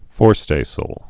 (fôrstāsəl, -sāl)